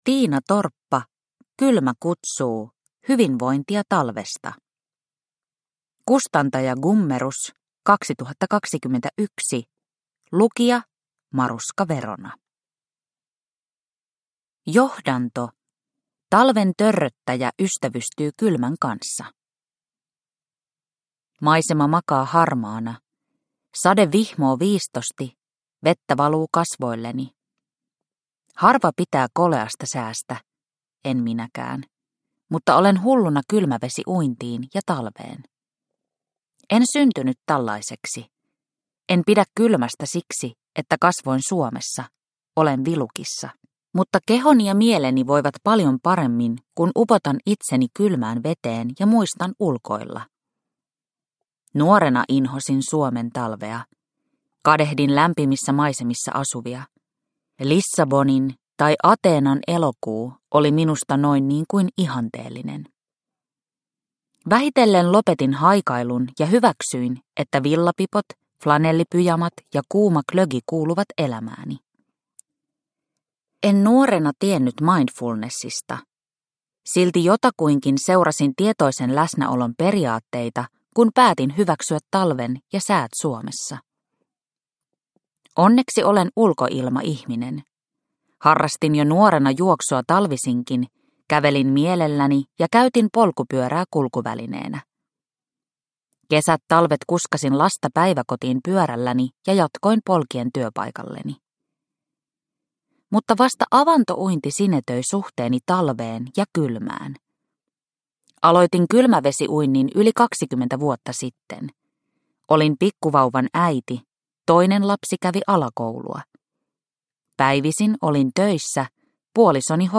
Kylmä kutsuu – Ljudbok – Laddas ner